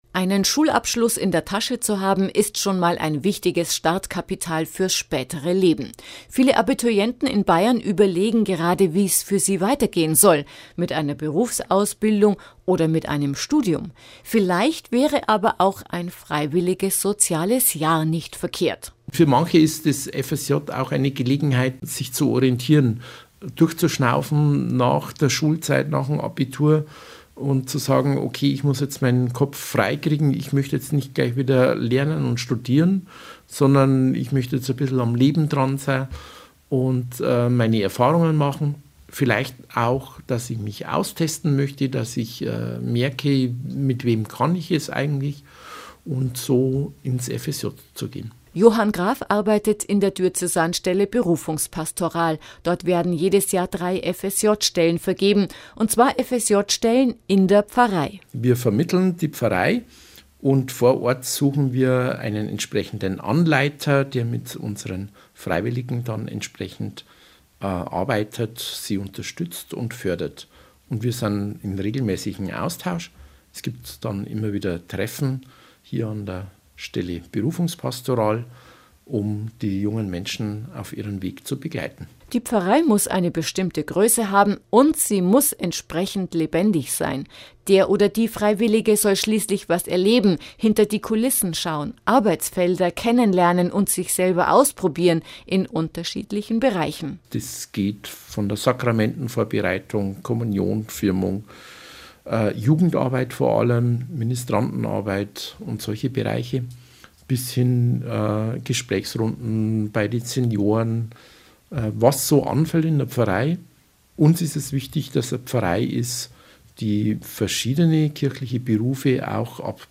FSJ im Interview